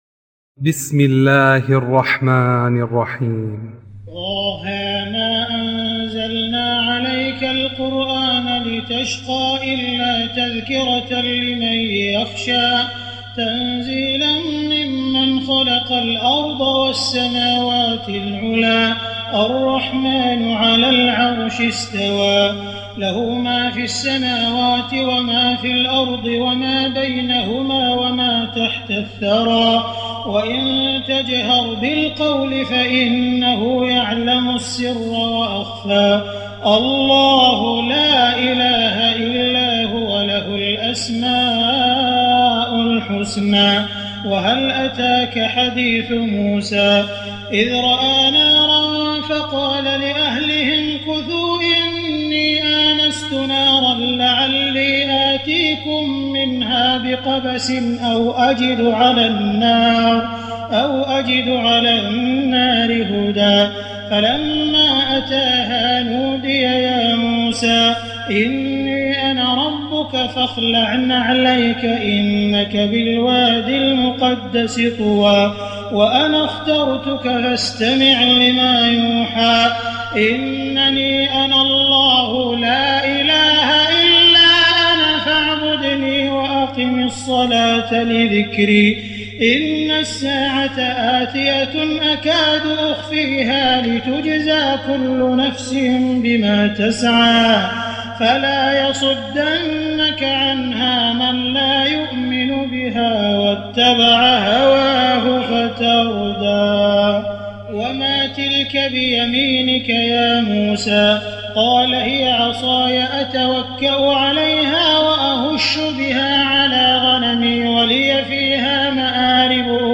تراويح الليلة الخامسة عشر رمضان 1419هـ سورة طه كاملة Taraweeh 15 st night Ramadan 1419H from Surah Taa-Haa > تراويح الحرم المكي عام 1419 🕋 > التراويح - تلاوات الحرمين